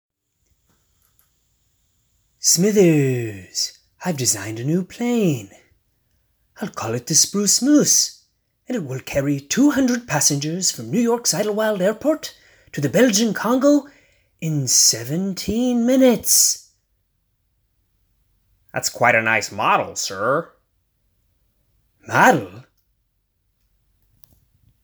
Tags: cartoon voiceover